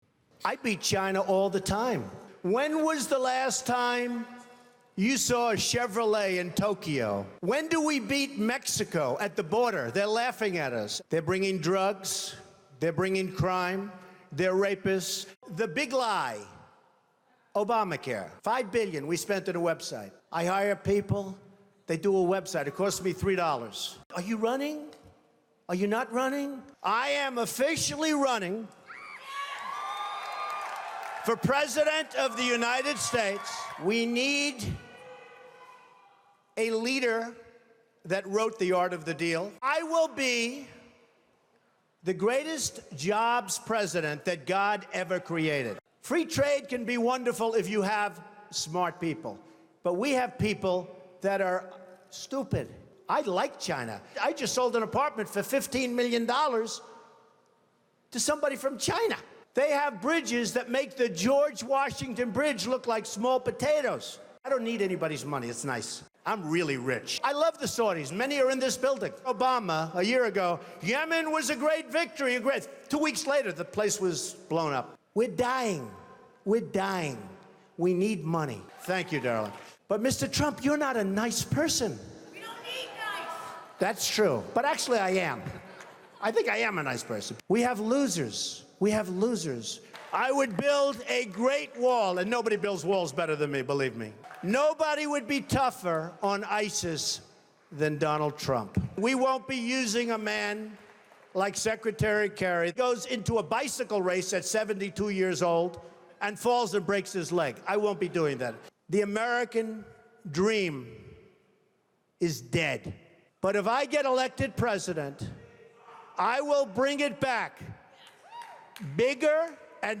Donald-Trump-Best-lines-during-2016-speech.mp3